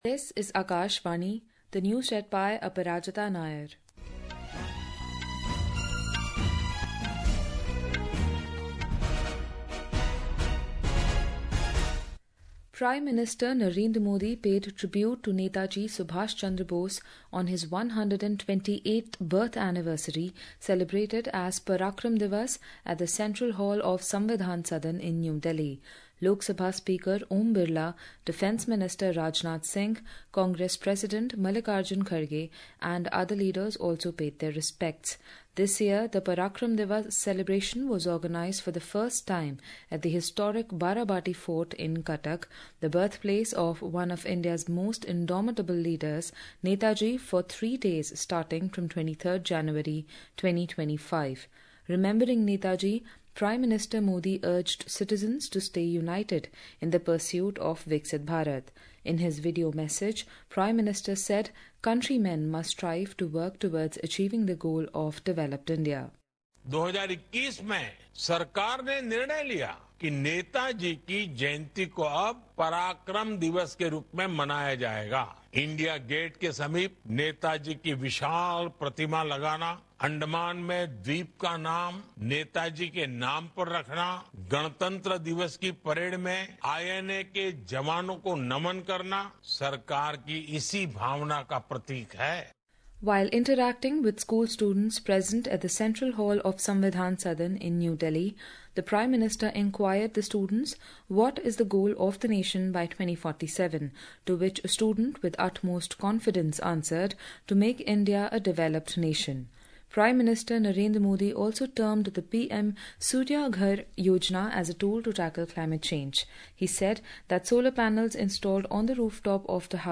قومی بلیٹنز
Hourly News